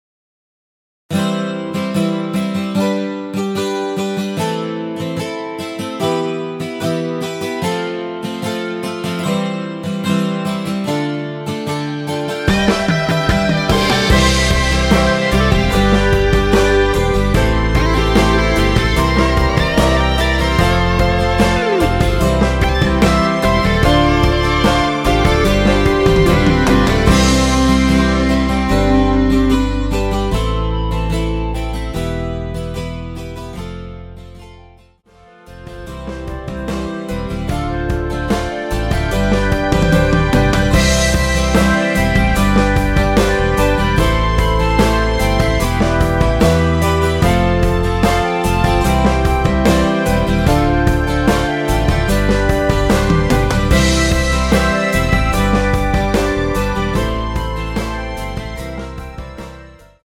원키에서(+5)올린 멜로디 포함된 MR입니다.
앞부분30초, 뒷부분30초씩 편집해서 올려 드리고 있습니다.